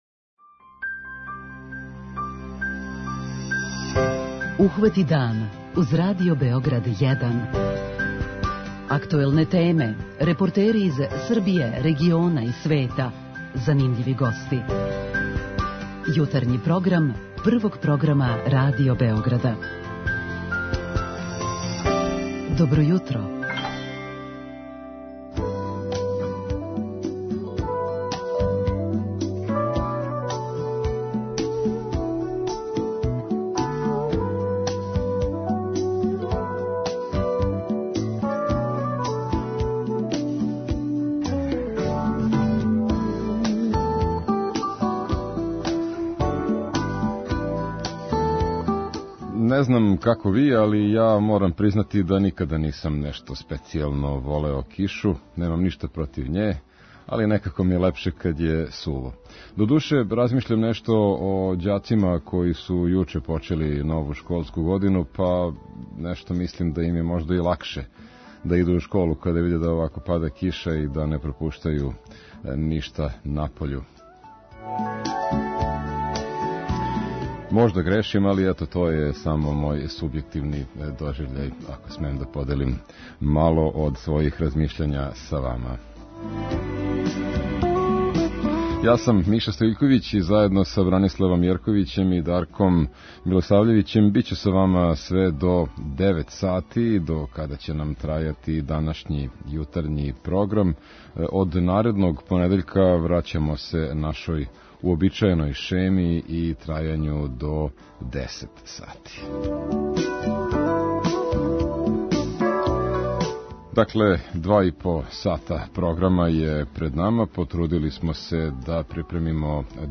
Самит лидера земаља западног Балкана почео је отварањем првог Међународног сајма вина 'Винска визија Отвореног Балкана' где је био и репортер Радио Београда чију ћемо репортажу чути у оквиру емисије.
Чућемо и шта кажу стручњаци за економију и привреду о томе какве ће бити кретања на тржишту током предстојеће јесени и зиме, а о тој теми разговараћемо и са слушаоцима у нашој редовној рубрици "Питање јутра".